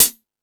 Hip House(15).wav